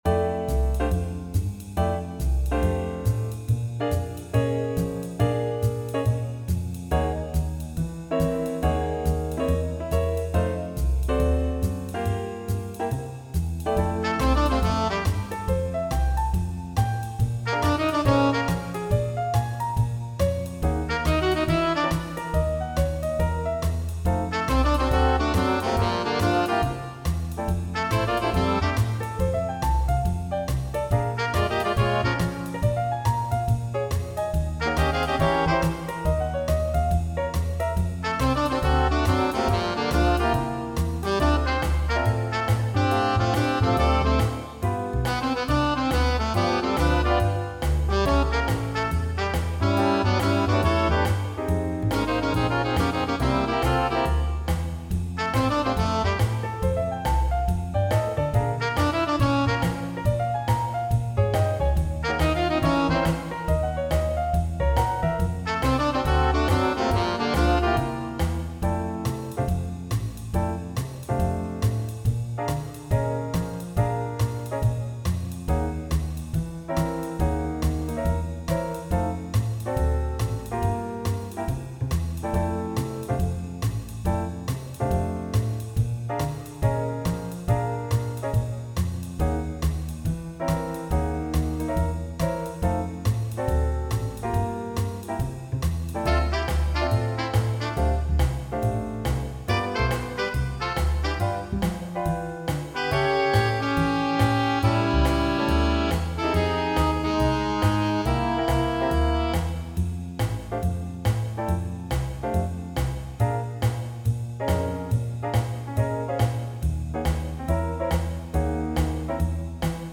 All audio files are computer-generated.